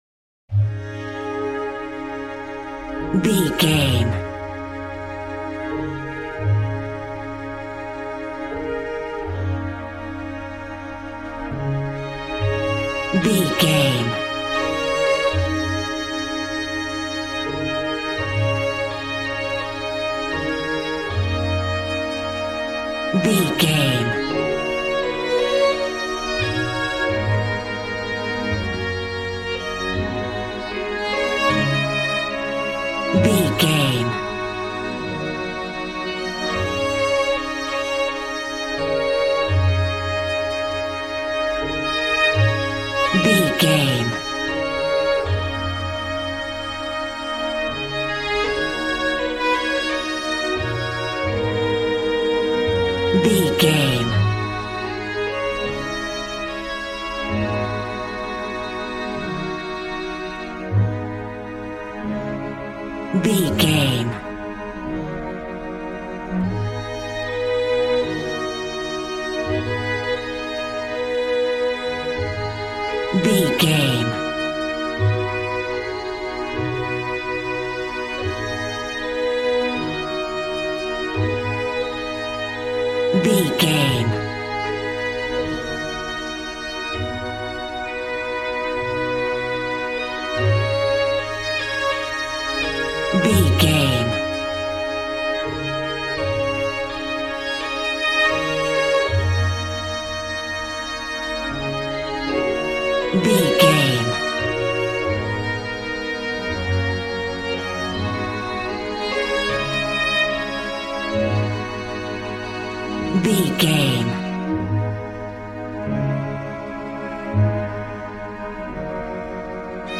Aeolian/Minor
E♭
joyful
conga
80s